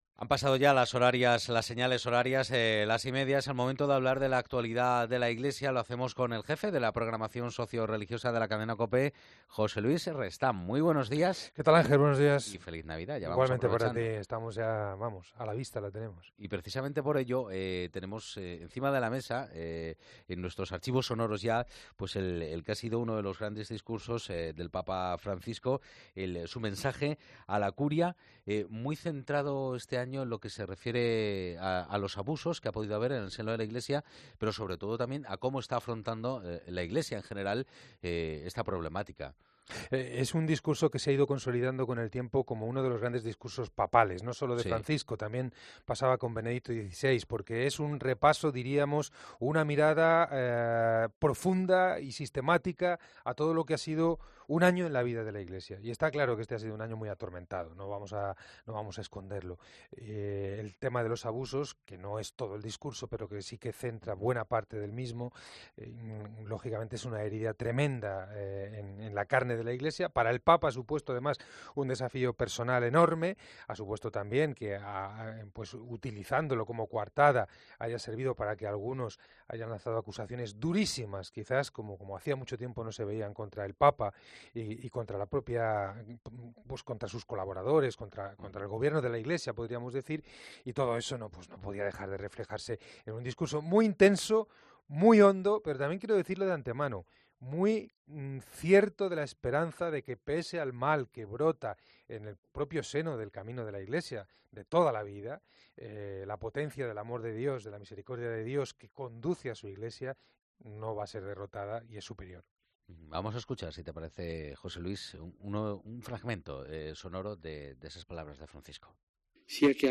Es el momento de hablar de la actualidad de la Iglesia.